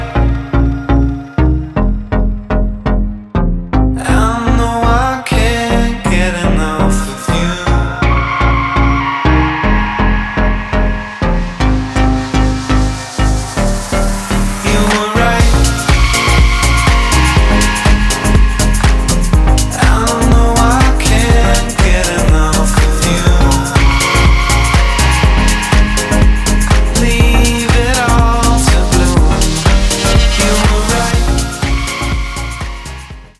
• Качество: 320, Stereo
красивый мужской голос
Electronic
спокойные
house
alternative dance